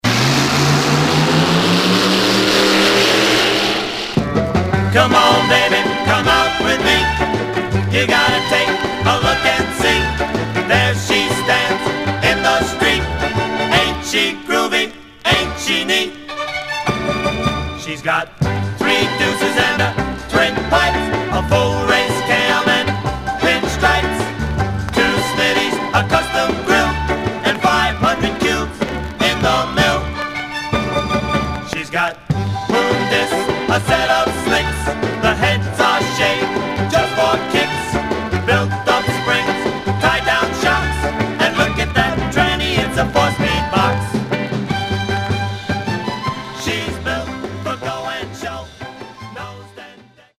Much surface noise/wear
Mono